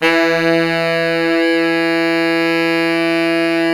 SAX_sfe3x    226.wav